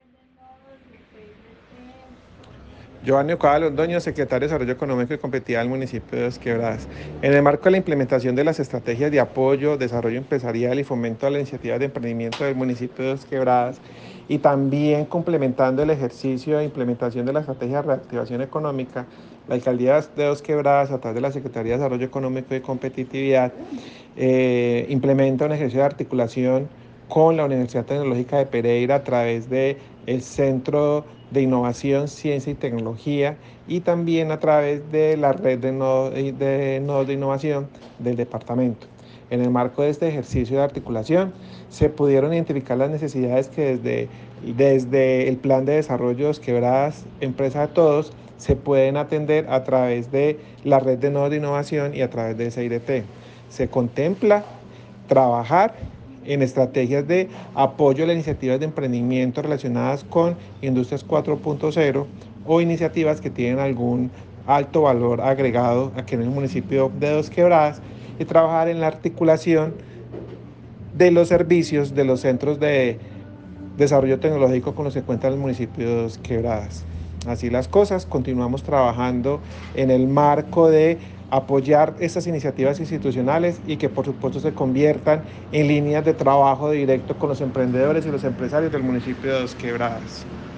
Comunicado-277-Audio-Secretario-de-Desarrollo-Economico-y-Competitividad-Geovanny-Ducuara-Londono.mp3